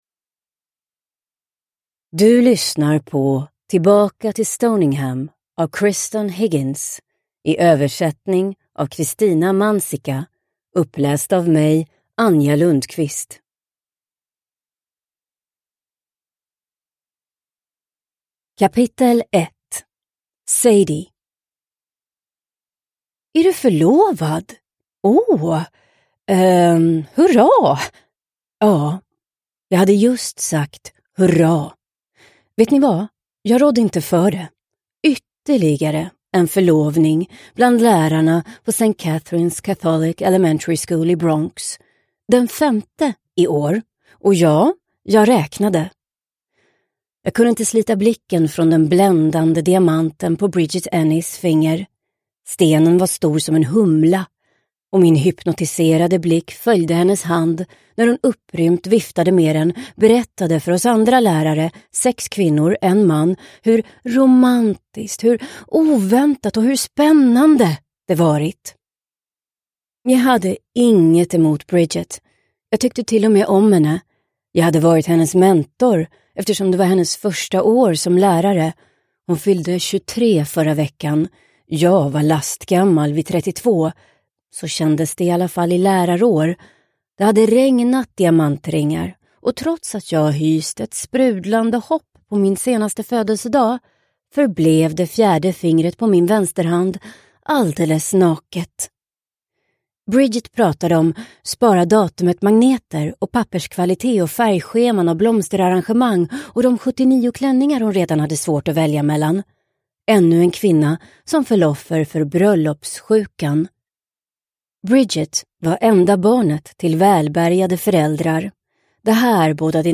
Tillbaka till Stoningham – Ljudbok – Laddas ner